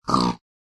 pig
should be correct audio levels.